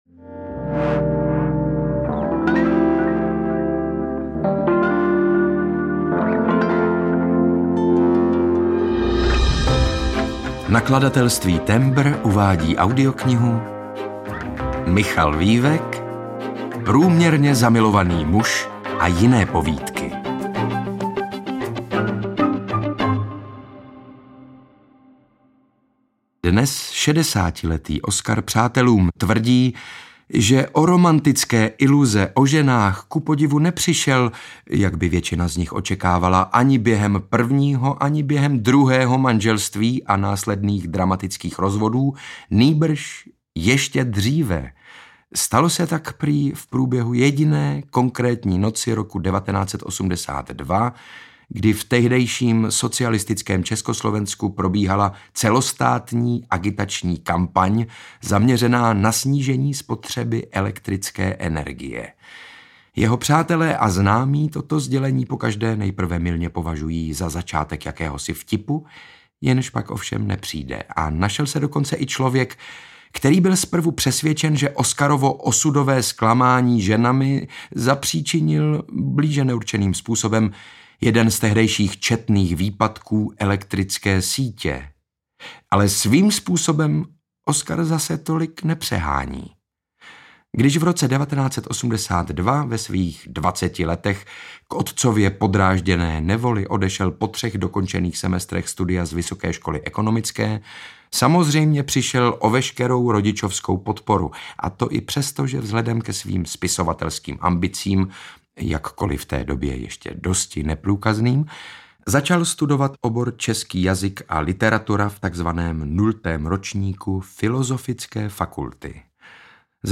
Ukázka z knihy
• InterpretSaša Rašilov, Jan Maxián, Martina Hudečková, Libor Hruška
prumerne-zamilovany-muz-a-jine-povidky-audiokniha